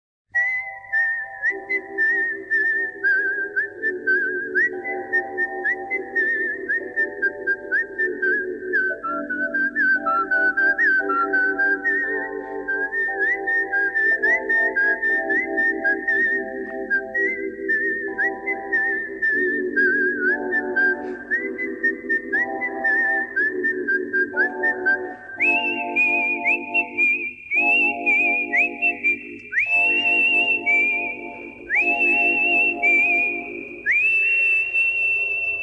Свист из к.ф.